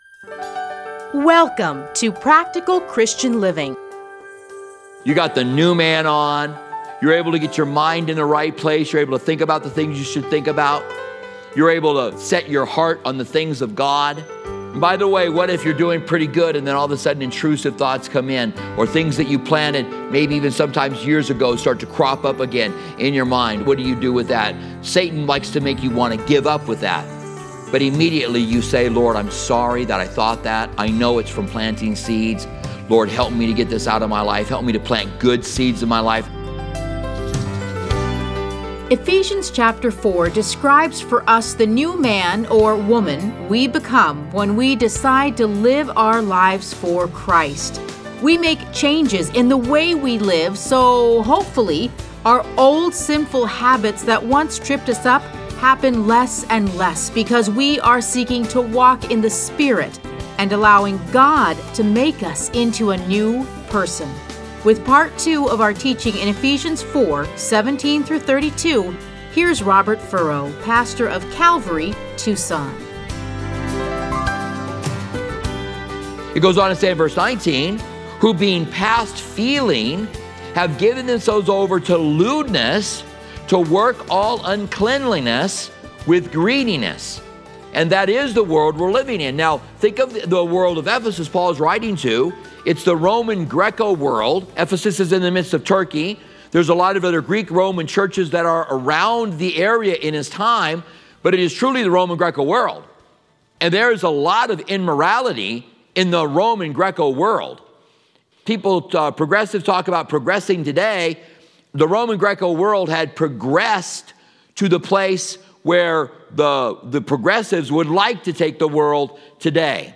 Listen to a teaching from Ephesians 4:17-32.